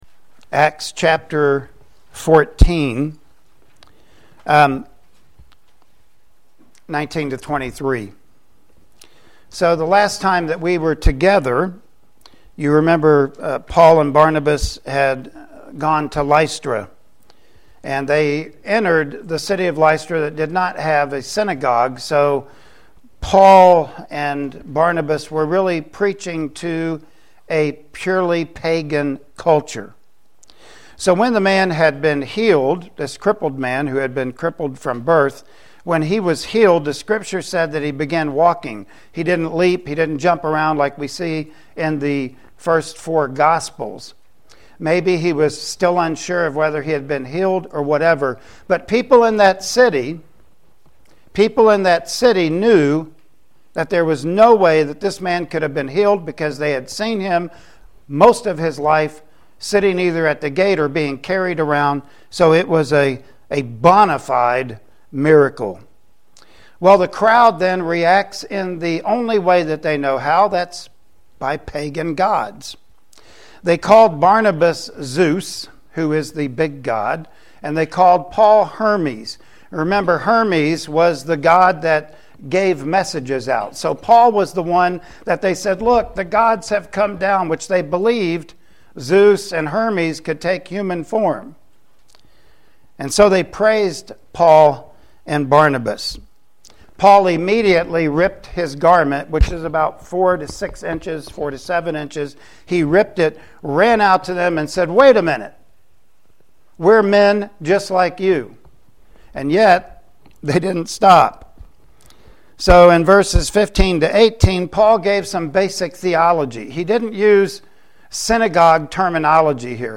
Acts 14 & 15 Passage: Acts 14:19-23 Service Type: Sunday Morning Worship Service Topics